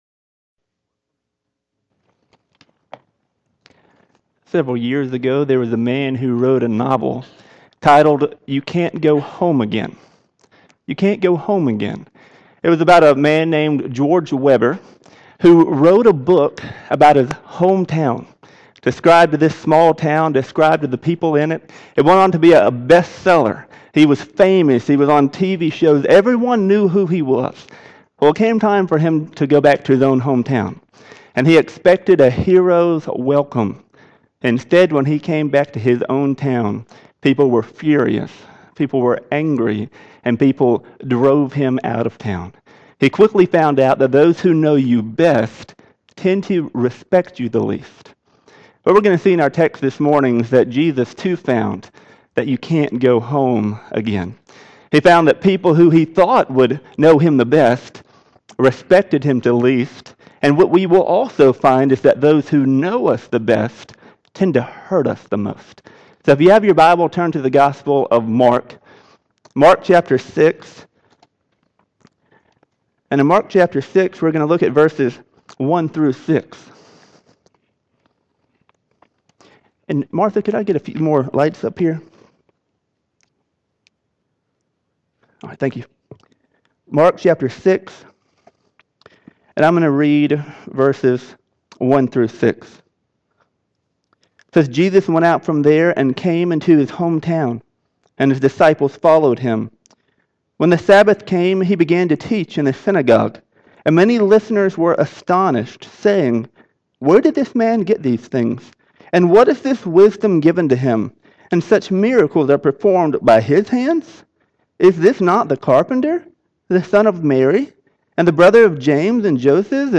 Audio Sermons: Videos of service can be seen on Facebook page | Trinity Baptist Church